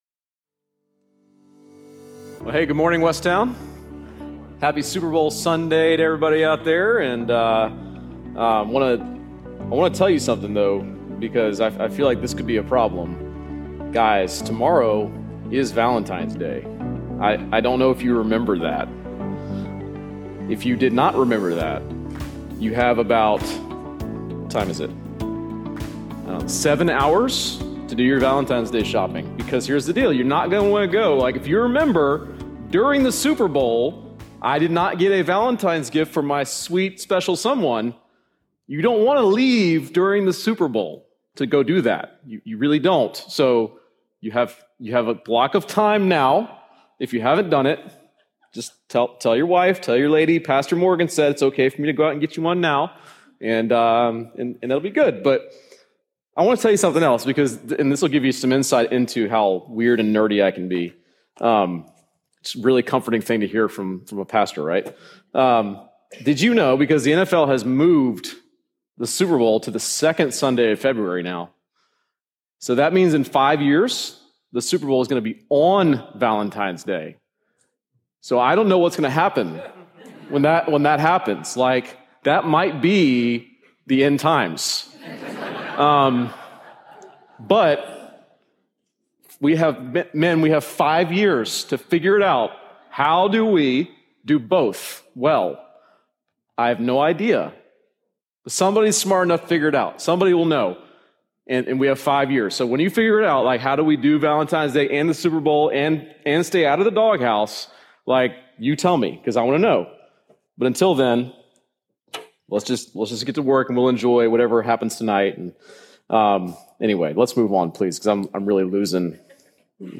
Join us in this 13 week sermon series through Joshua and discover what it looks like to find our strength and courage in the Lord!